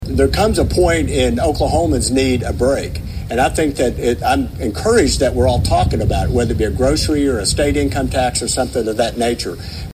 CLICK HERE to listen to commentary from Senator Darrell Weaver.